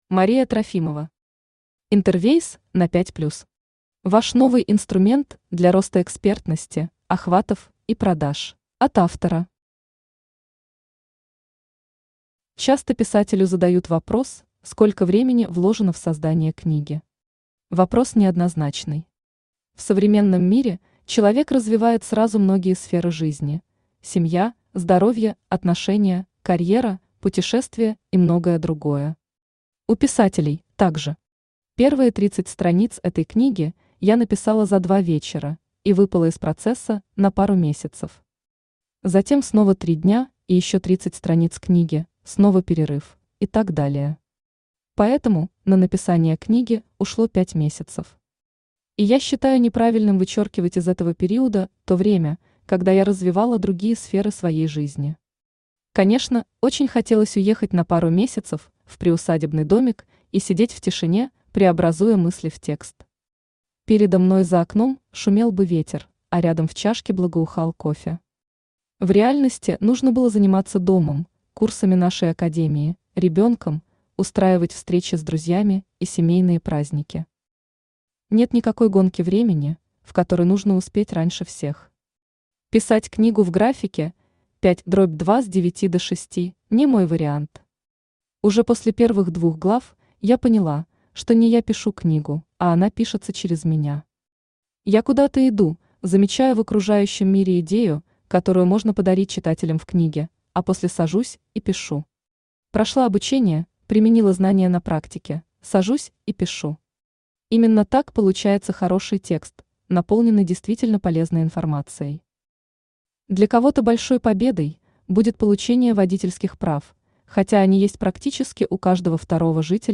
Аудиокнига Интервейс на 5+. Как провести интервью с клиентом и получить многофункциональный кейс-отзыв для продвижения бизнеса | Библиотека аудиокниг